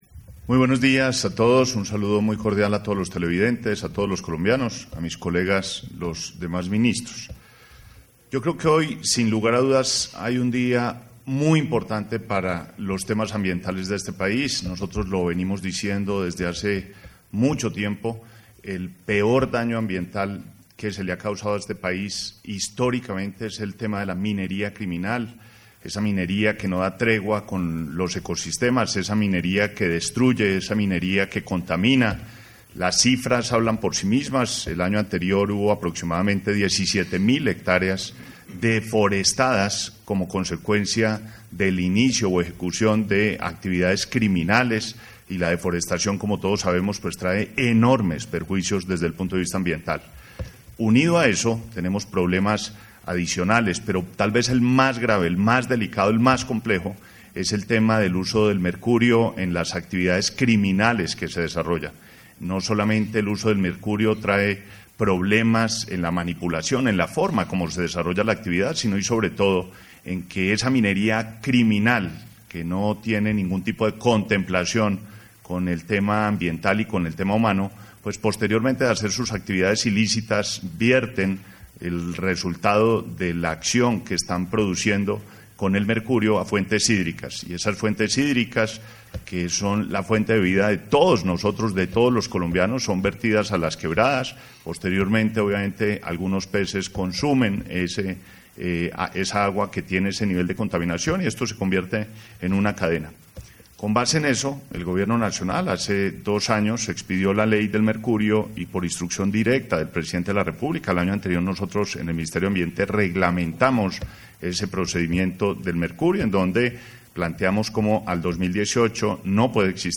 31-rueda_prensa_31_julio_1.mp3